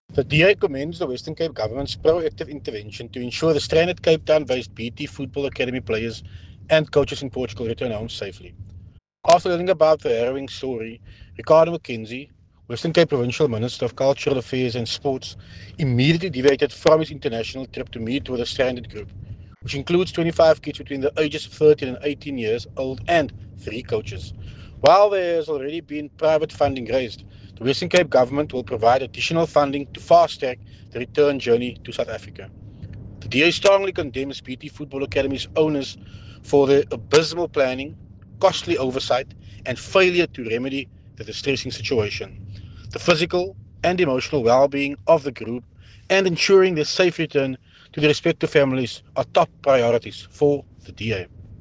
Issued by Tertuis Simmers – DA Western Cape Leader
soundbite by Tertuis Simmers